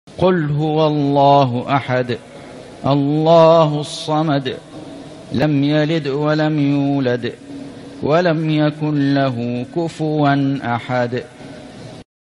سورة الإخلاص > السور المكتملة للشيخ فيصل غزاوي من الحرم المكي 🕋 > السور المكتملة 🕋 > المزيد - تلاوات الحرمين